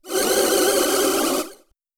Creature 002.wav